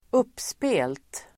Ladda ner uttalet
uppspelt adjektiv, in high spirits Uttal: [²'up:spe:lt]